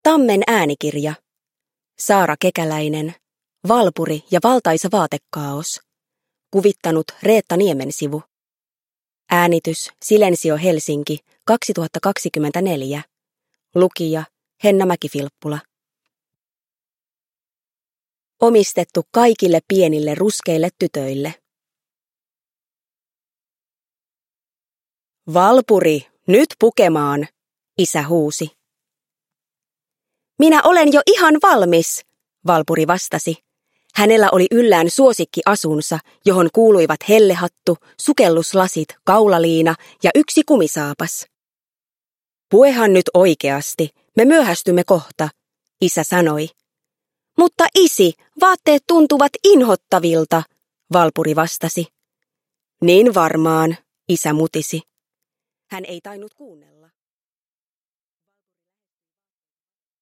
Valpuri ja valtaisa vaatekaaos – Ljudbok